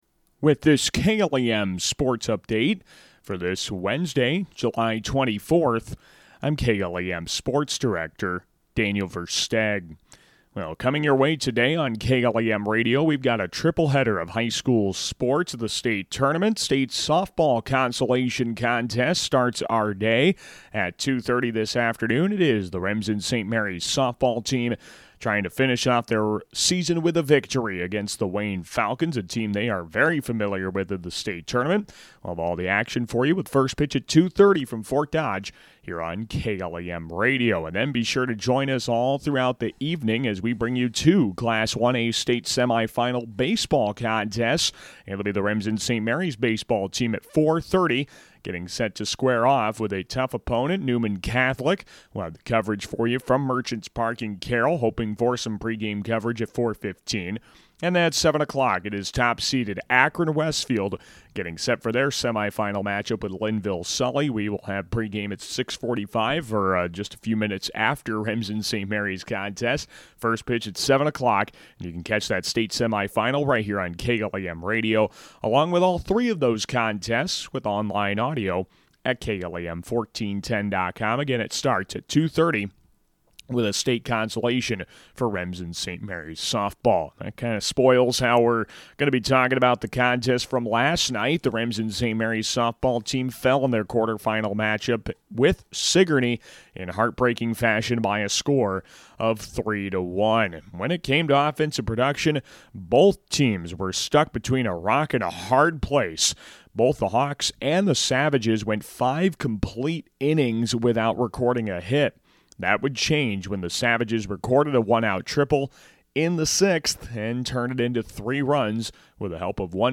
July 24, 2024 – Sportscast